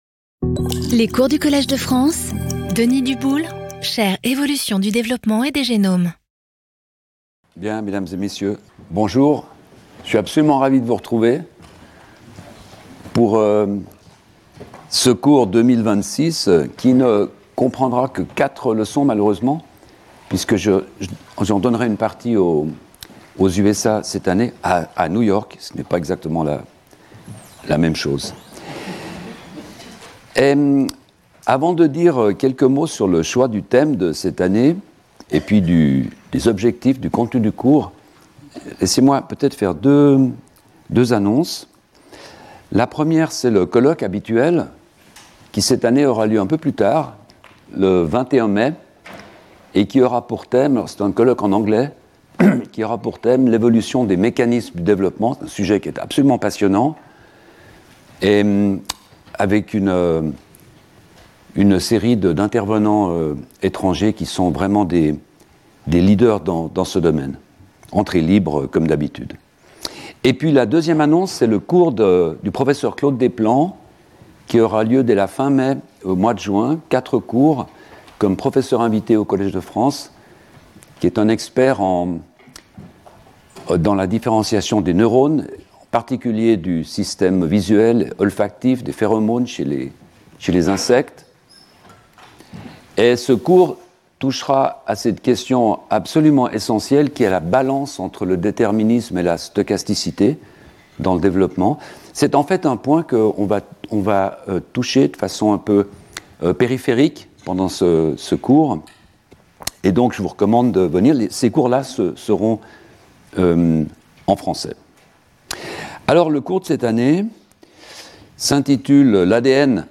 Intervenant(s) Denis Duboule Professeur du Collège de France Événements Précédent Cours 06 Mar 2026 10:00 à 11:30 Denis Duboule L’ADN, cause proximale ou cause ultime de notre évolution ?